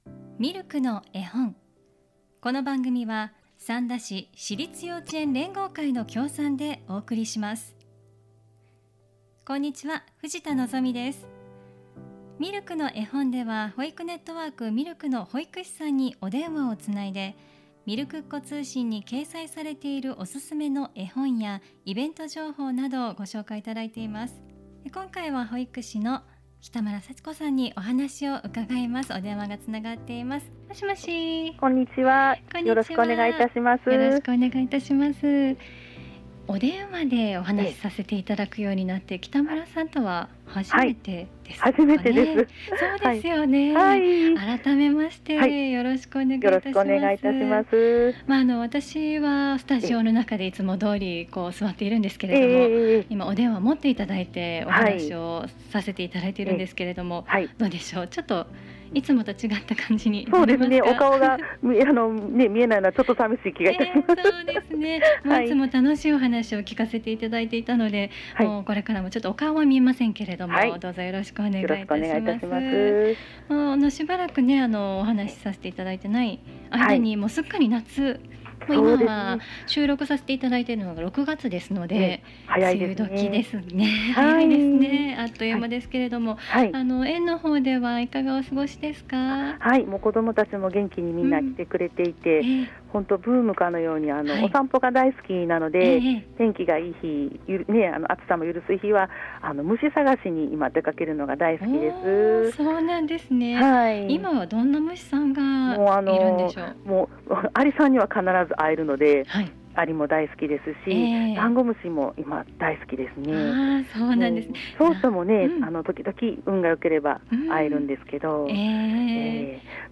引き続き 保育ネットワーク・ミルクの保育士さんにお電話をつないで、みるくっ子通信に掲載されているおすすめの絵本やイベント・施設情報などお聞きします。